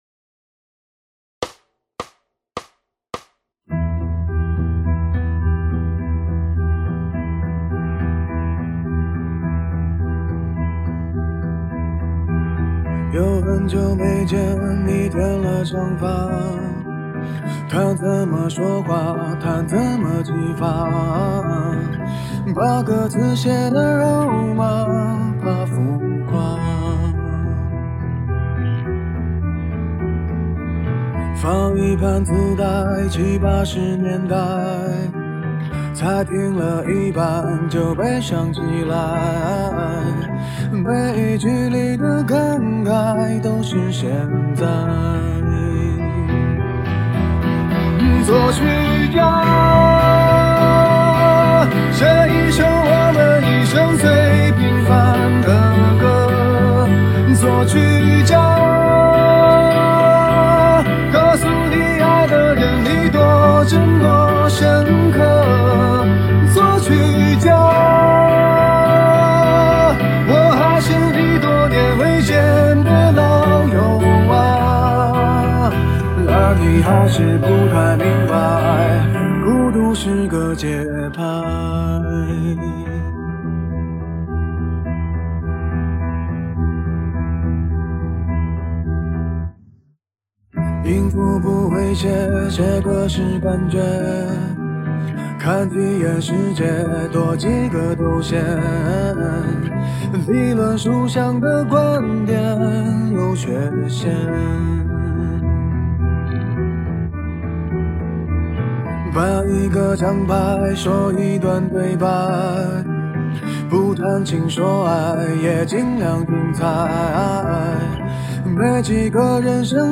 无鼓伴奏+纯鼓声+节拍器